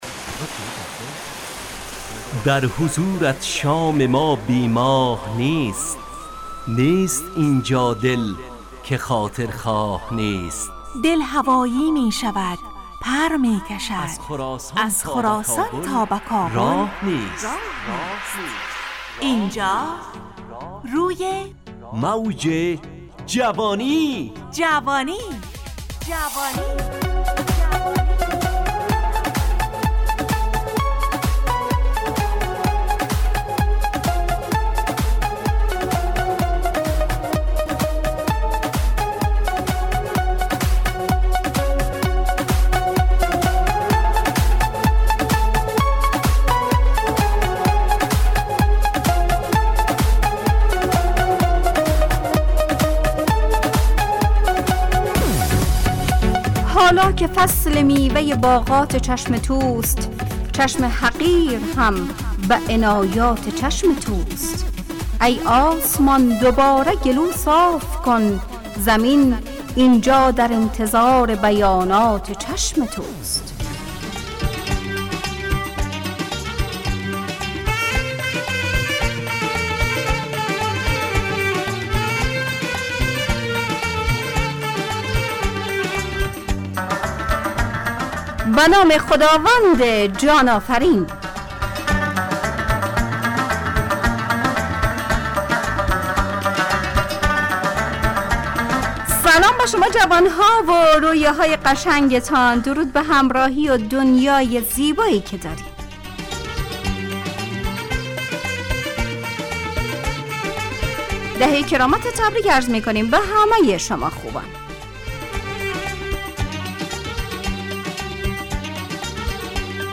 روی موج جوانی، برنامه شادو عصرانه رادیودری.
از شنبه تا پنجشنبه ازساعت 4:45 الی5:55 به وقت افغانستان، طرح موضوعات روز، وآگاهی دهی برای جوانان، و.....بخشهای روزانه جوان پسند. همراه با ترانه و موسیقی مدت برنامه 70 دقیقه .